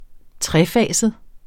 Udtale [ ˈtʁεˌfæˀsəð ]